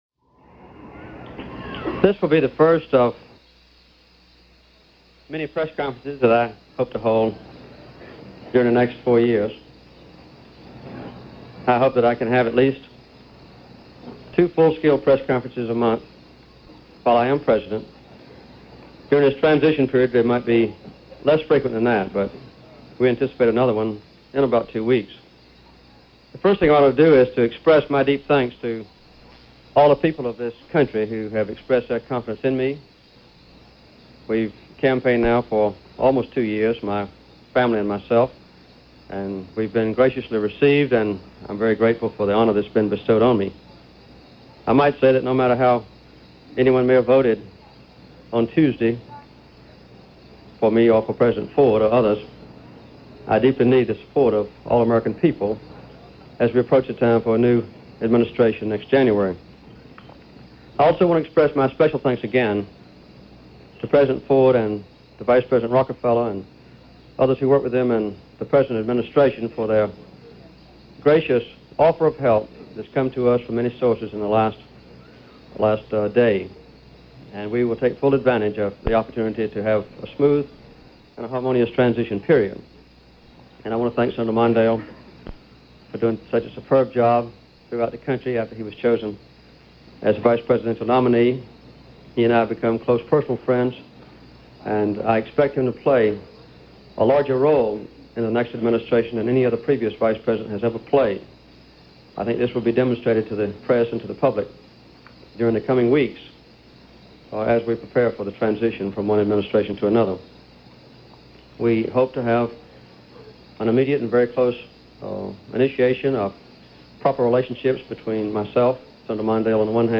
President Elect Jimmy Carter - First press Conference - November 4, 1976 - Presidential Press conference - all networks
President-Elect Jimmy Carter, in his first press conference since winning the White House, from November 4, 1976. In retrospect probably not an earth-shattering press conference, certainly sedate by todays standards, but a press conference which embodied the basic concept of “being Presidential“.
Pres.-Elect-Carter-1st-presser-1976.mp3